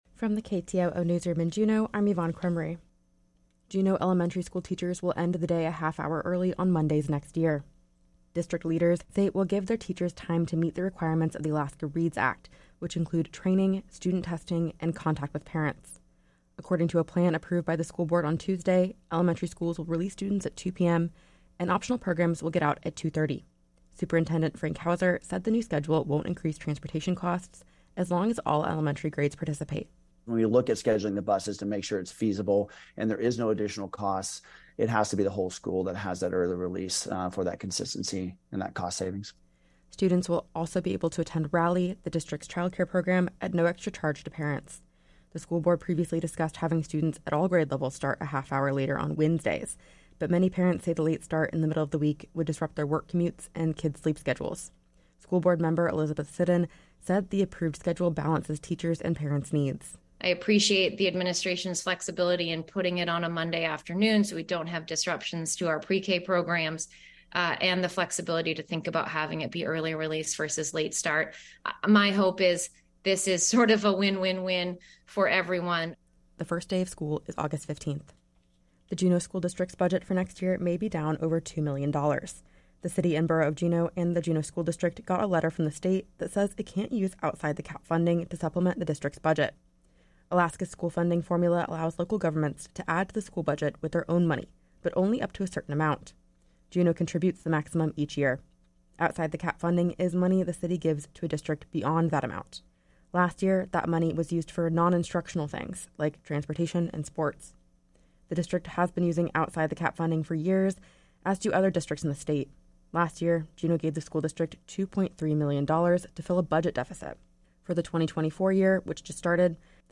Newscast – Thursday, July 13, 2023